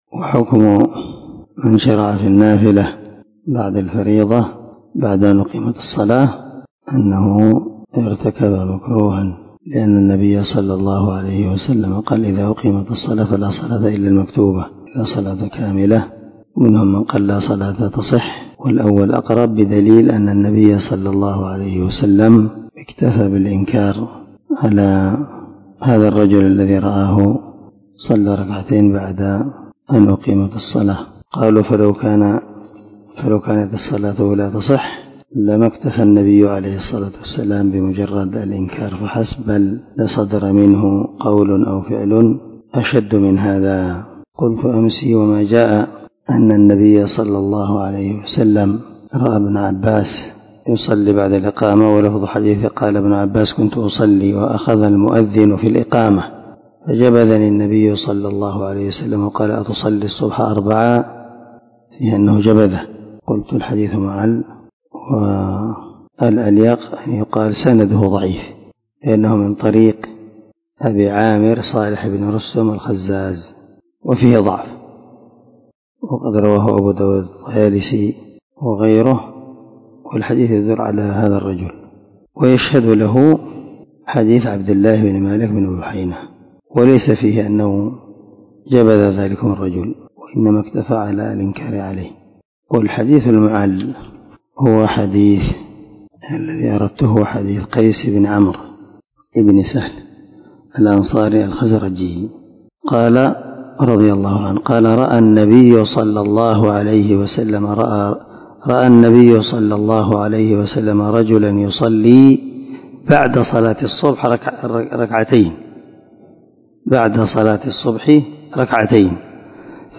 450الدرس 33 من شرح كتاب الأذان حديث رقم ( 664 ) من صحيح البخاري
دار الحديث- المَحاوِلة- الصبيحة.